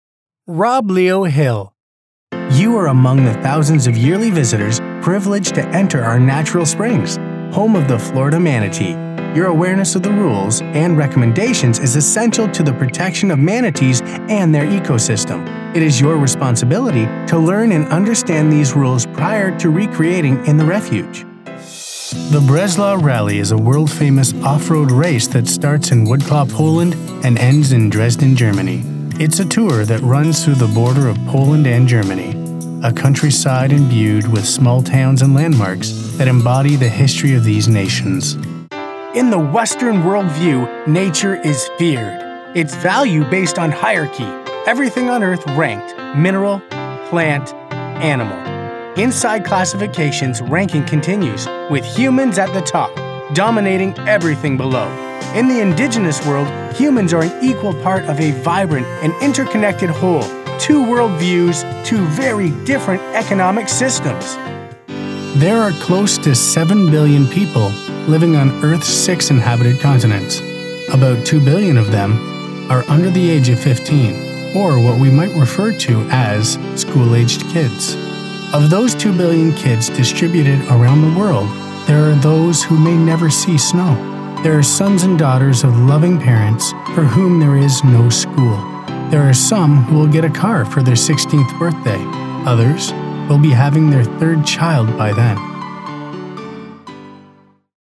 Guy Next Door, Young, Energetic and Natural.
Sprechprobe: Industrie (Muttersprache):
My home studio allows me to offer my clients profession recordings quickly and efficiently.